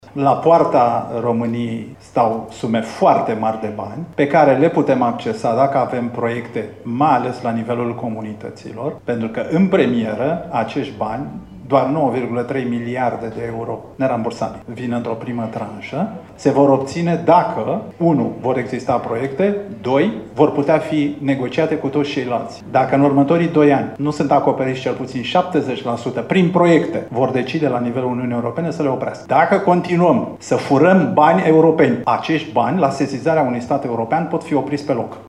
Prim vicepreşedintele Partidului Mişcarea Populară, Cristian Diaconescu a vorbit la Tîrgu Mureş, în fața candidaților partidului la primăriile din județul Mureș despre rolul pe care îl vor avea primarii în atragerea viitoarelor fonduri europene: